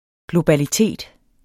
Udtale [ globaliˈteˀd ]